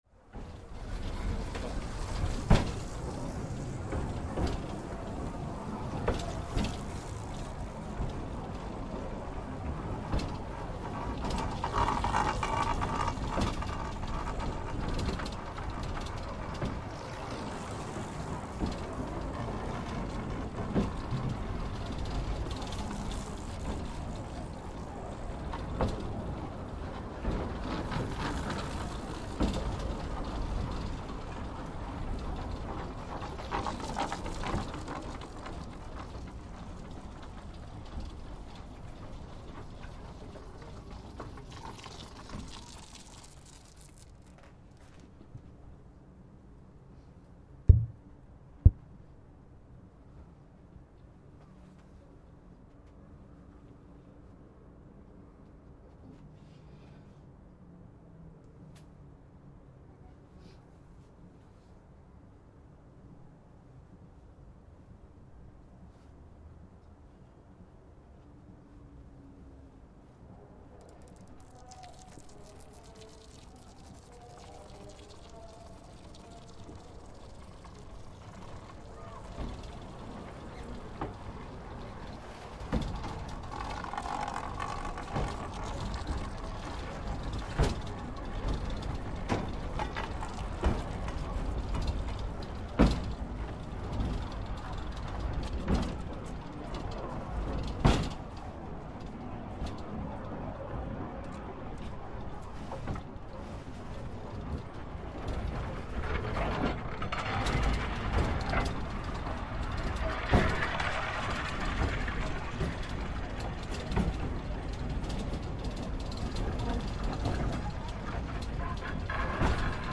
Aboard the clanking funicular track to the top of the mighty Cerro Santo Cristobal in Santiago.